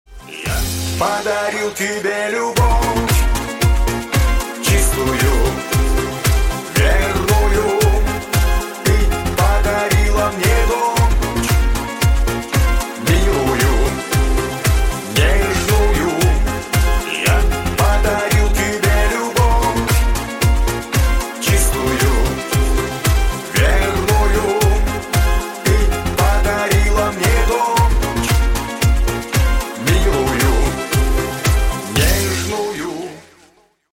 Рингтоны Ремиксы » # Шансон